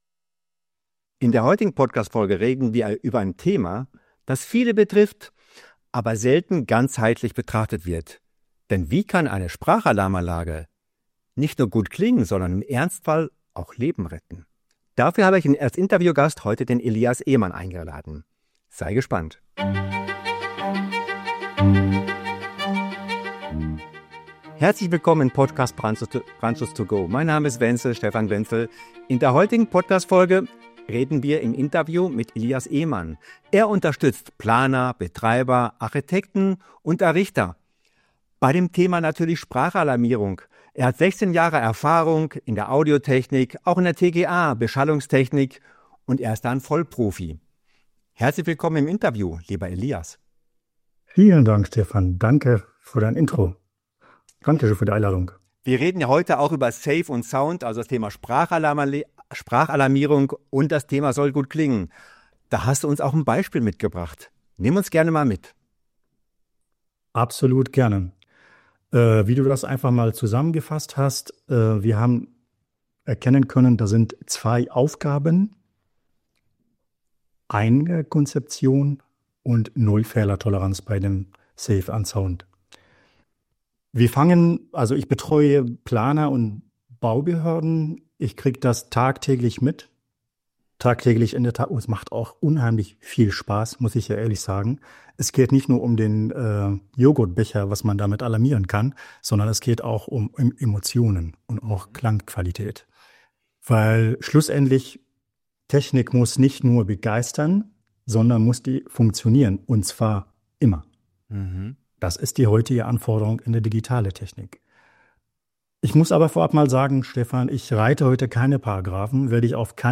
#198 Interview